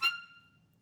DCClar_stac_F5_v2_rr2_sum.wav